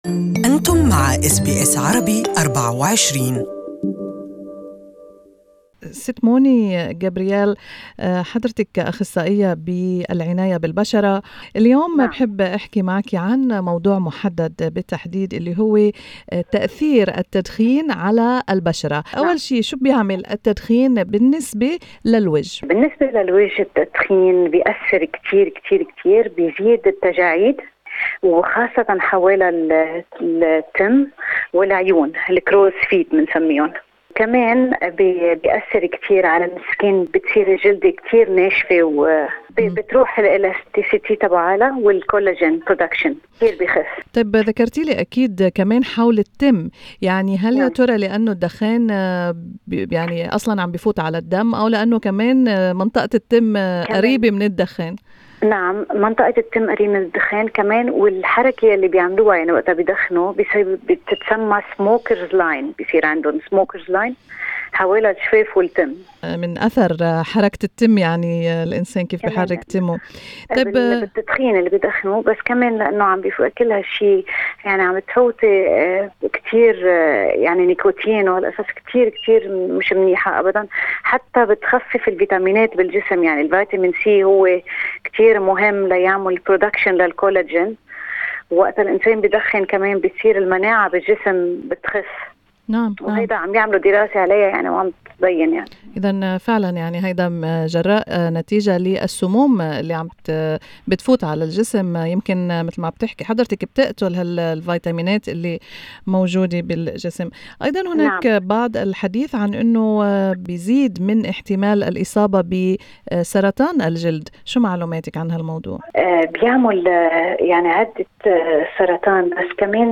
استمعوا إلى اللقاء كاملا تحت الشريط الصوتي.